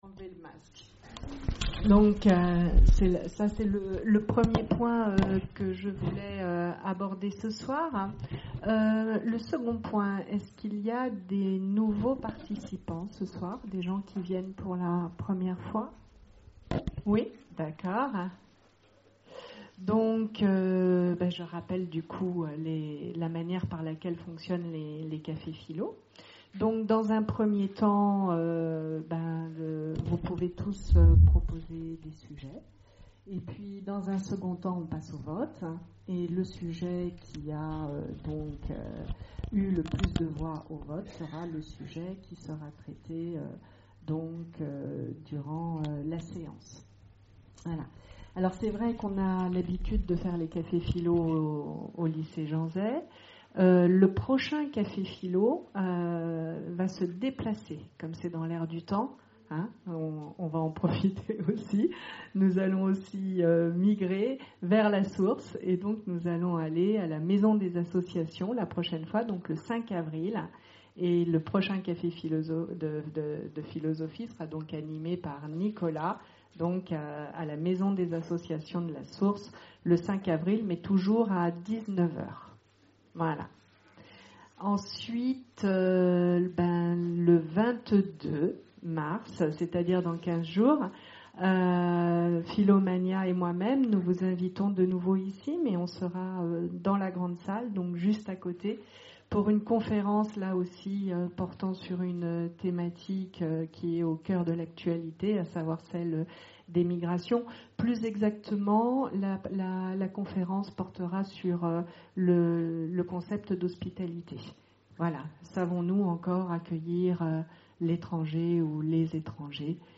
Conférences et cafés-philo, Orléans
CAFÉ-PHILO PHILOMANIA Peut-on être heureux sans être égoïste ?